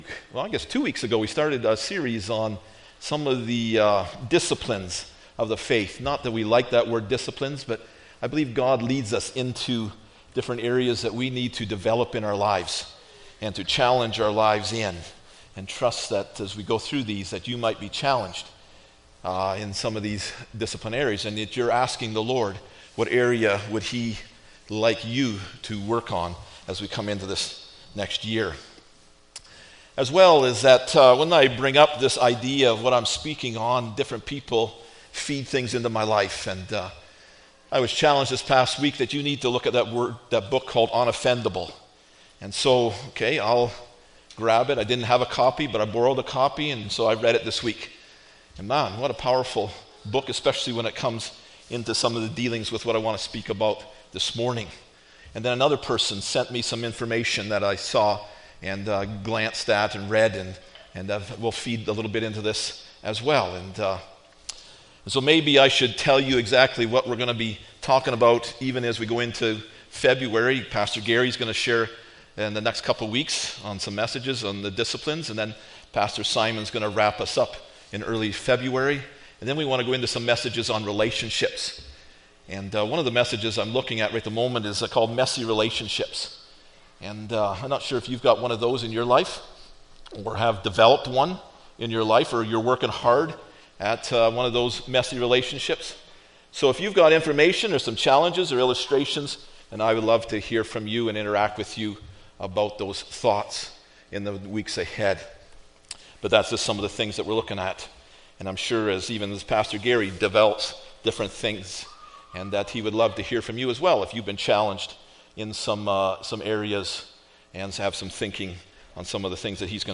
Hebrews 12:15 Service Type: Sunday Morning Bible Text